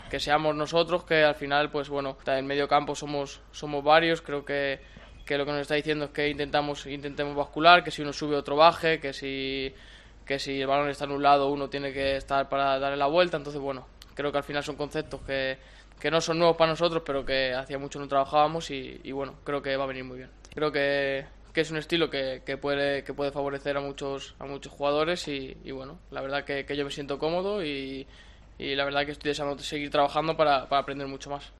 Tras la sesión de este jueves, ha comparecido ante los medios el mediocentro Fran Beltrán que ha revelado las primeras sensaciones del plantel después del triunfo en Sevilla y los primeros días con Claudio Giráldez al frente del primer equipo.